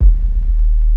41 BASS 01-R.wav